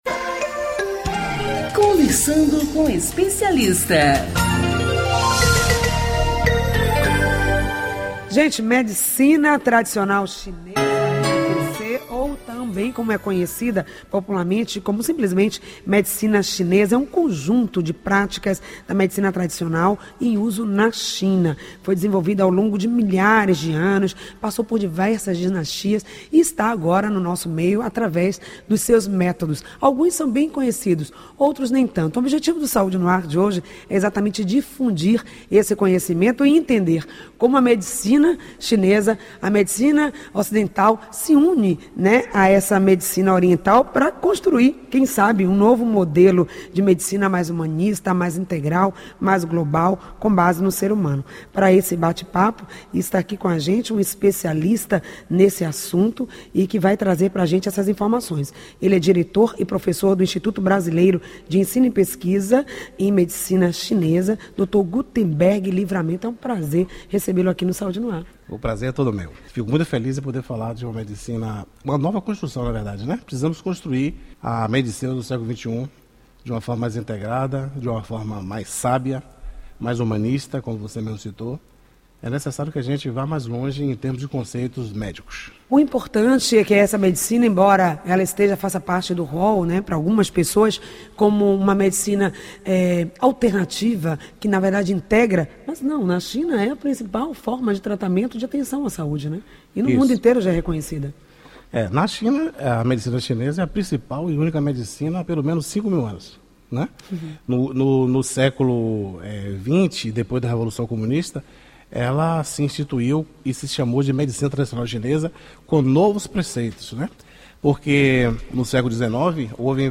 O assunto foi tema do Saúde no ar do dia 23.08.18. Ouça a entrevista abaixo: